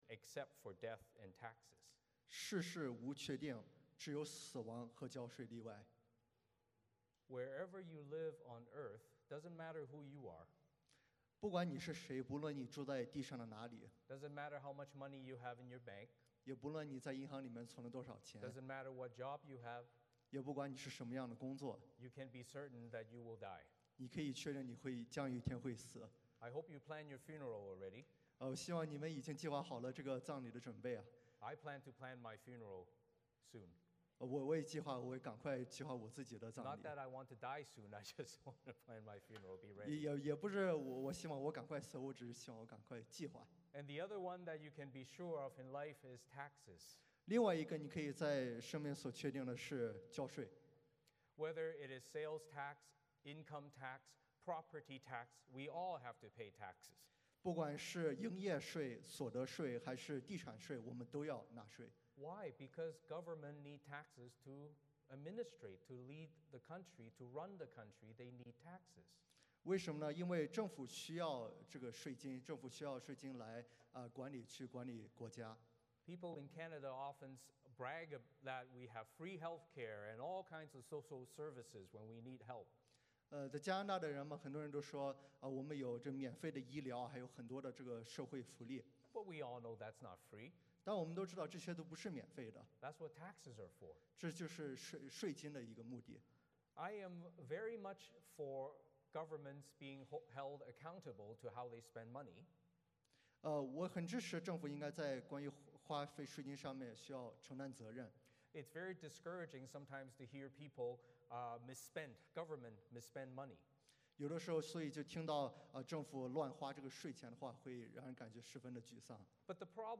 宣道会的异象祈祷 宣召 诗篇 34:1-3 诗歌 / 祷告 我的神，我的父，我的磐石 我们高举耶稣的名 我的生命献给祢 奉献 每天的祷告 读经 申命记 14:22-29 (圣经当代译本) 证道 一间乐于奉献的教会 回应诗 奉献所有 三一颂 领受祝福 欢迎/家事分享
Service Type: 主日崇拜 欢迎大家加入我们的敬拜。